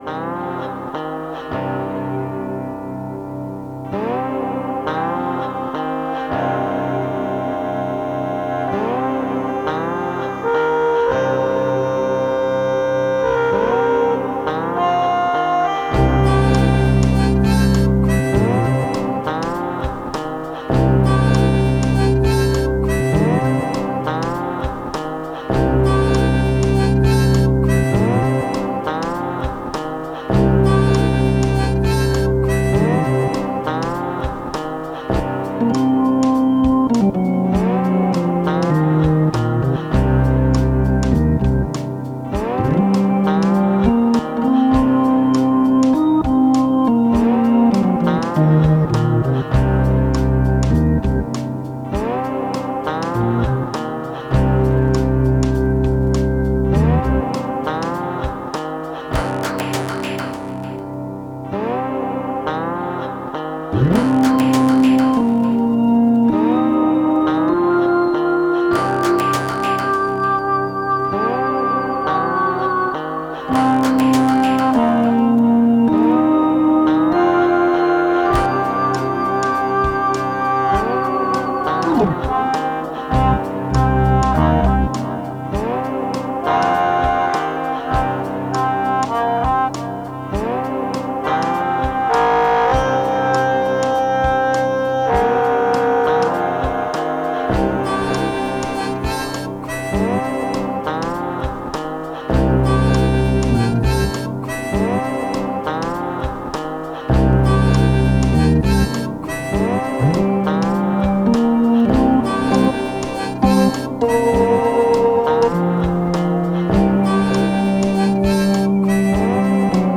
Dark amtosphere. Folk Hammond Melodion pending with guitar.